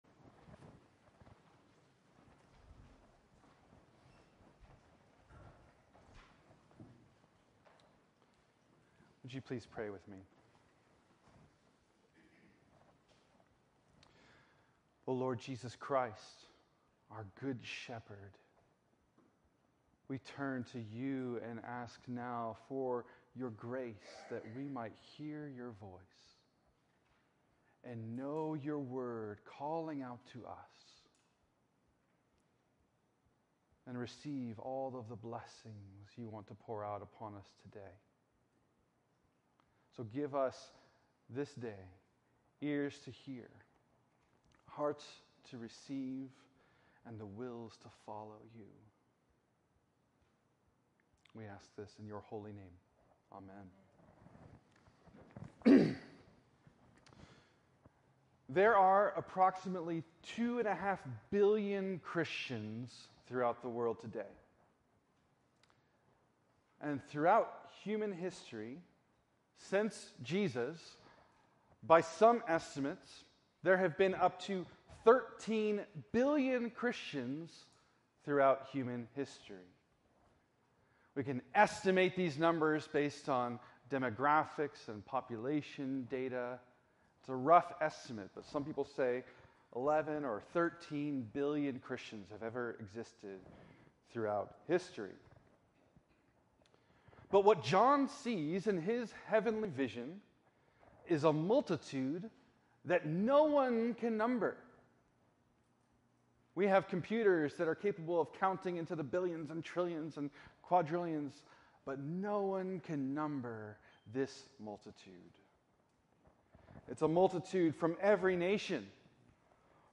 Show Audio Player Save Audio In this sermon on the fourth Sunday of Easter